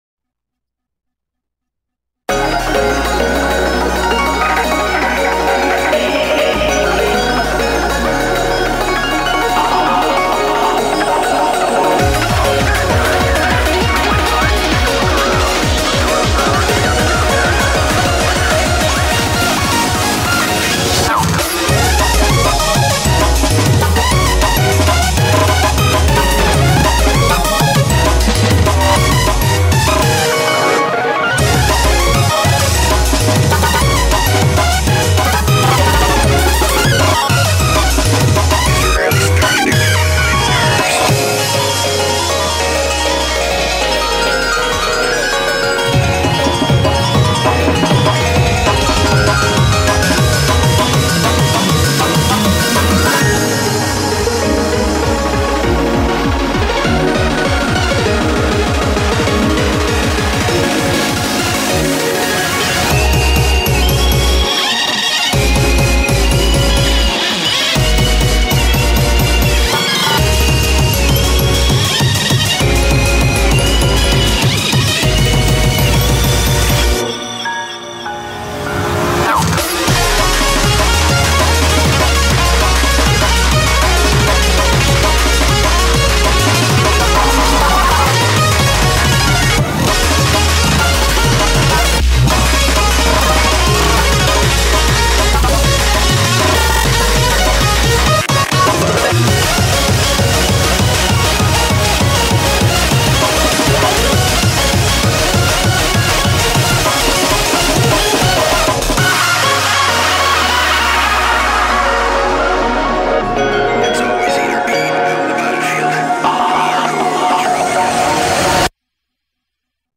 BPM99-396
Audio QualityPerfect (High Quality)
breakcore